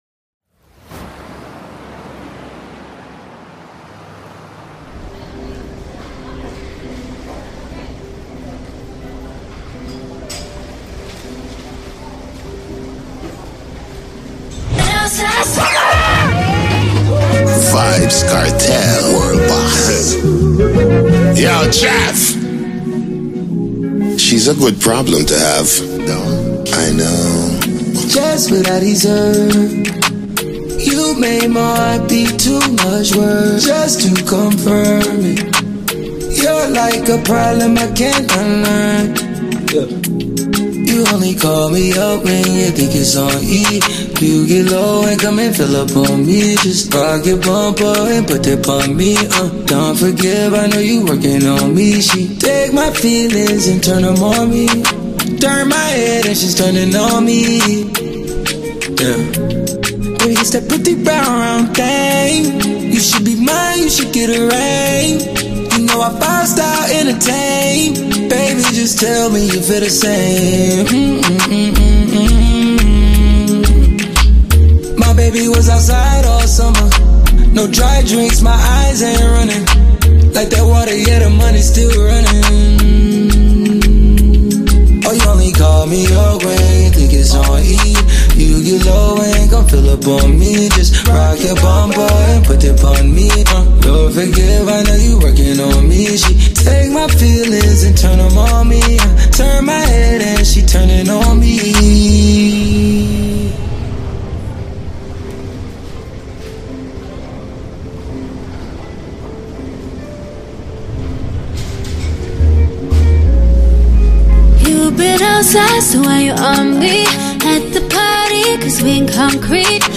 raw dancehall energy